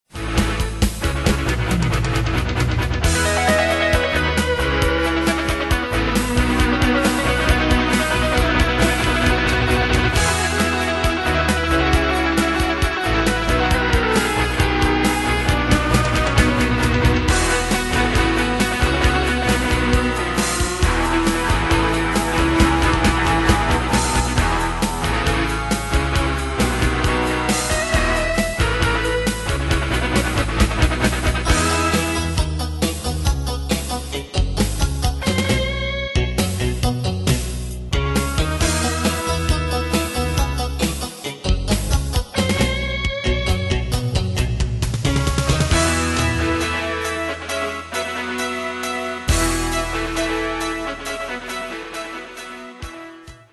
Style: PopAnglo Ane/Year: 1980 Tempo: 135 Durée/Time: 2.49
Danse/Dance: Rock Cat Id.
Pro Backing Tracks